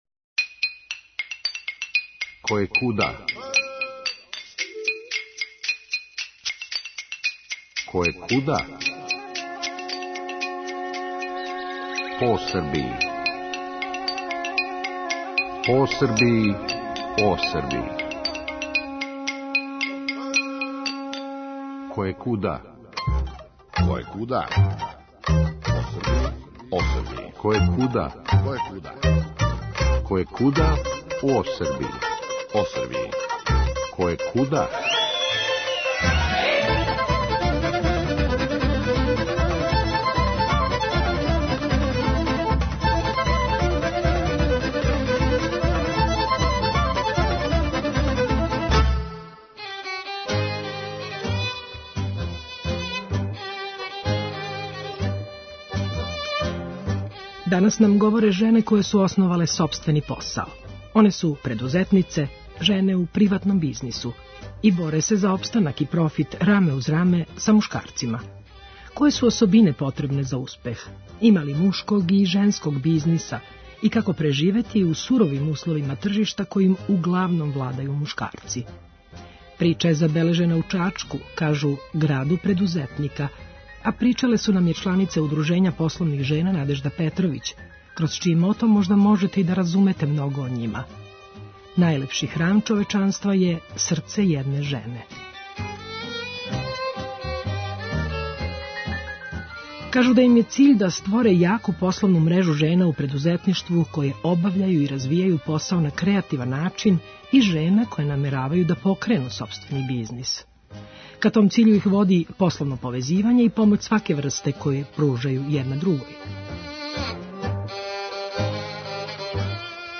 Данас нам говоре жене које су основале сопствени посао.